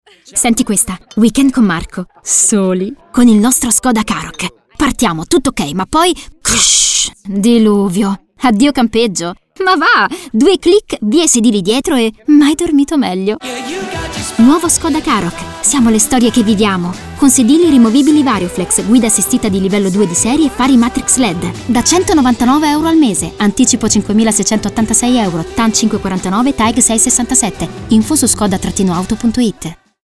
Demo
Attrice, doppiatrice e speaker.